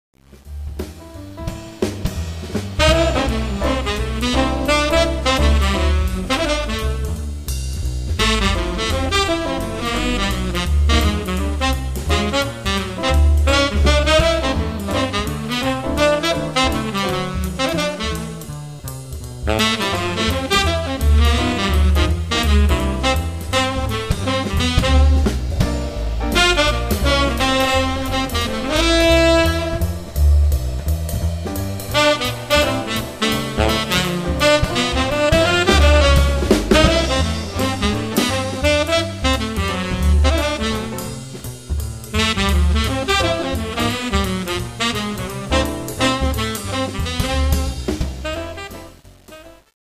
Recorded at Sanctuary Studios, Broadalbin, NY 2004-2011